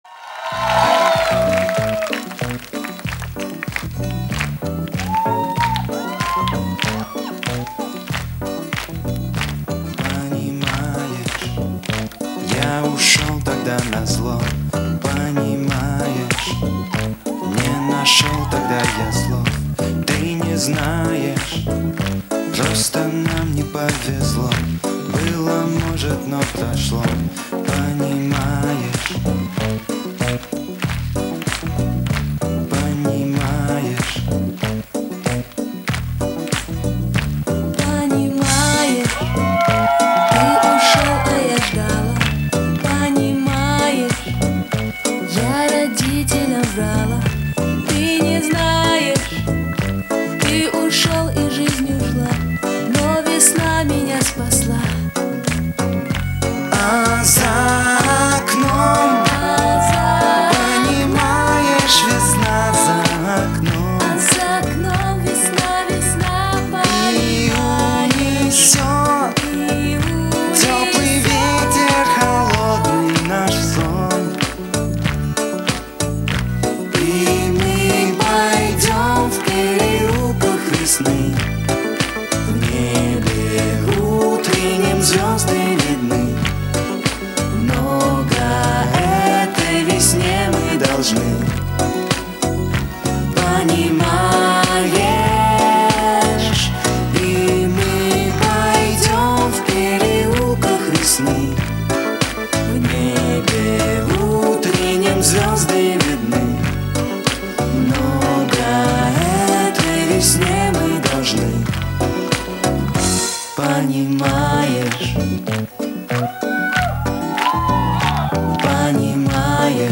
Запись была сделана мной с Тюнера AverMedia TV-Phon